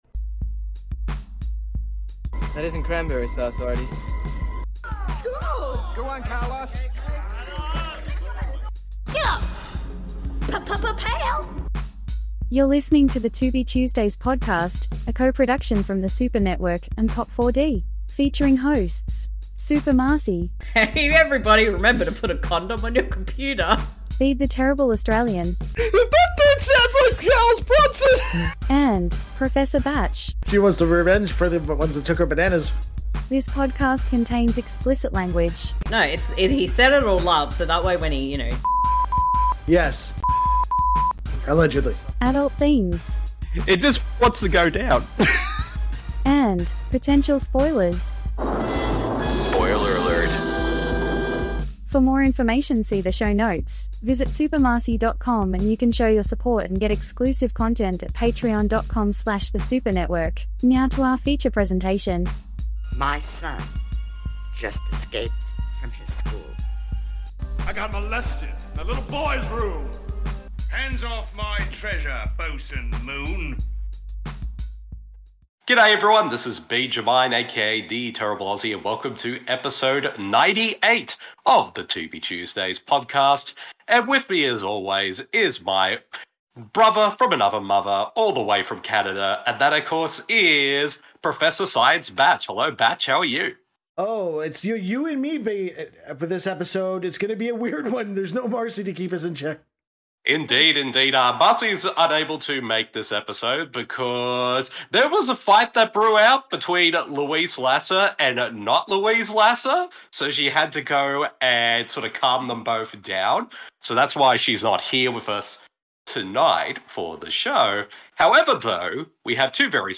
Please note there were some technical difficulties during the recording.